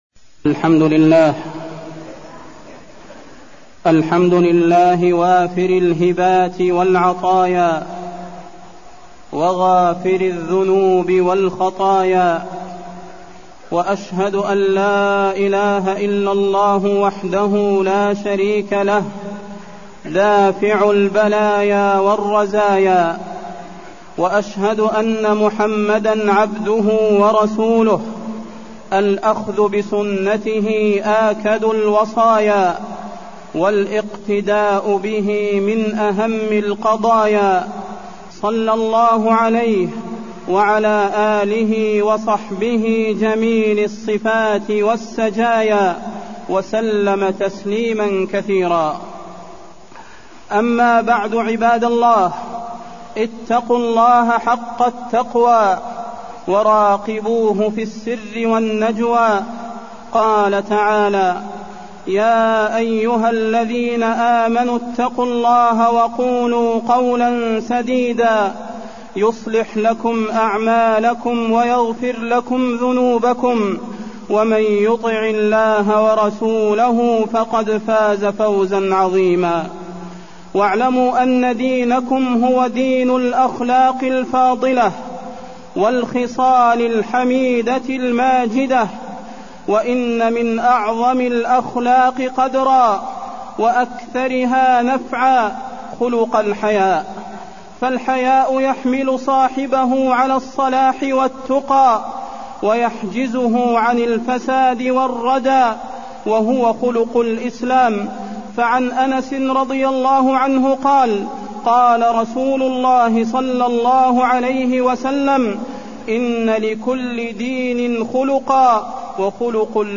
تاريخ النشر ١٢ ذو القعدة ١٤٢٠ هـ المكان: المسجد النبوي الشيخ: فضيلة الشيخ د. صلاح بن محمد البدير فضيلة الشيخ د. صلاح بن محمد البدير الحياء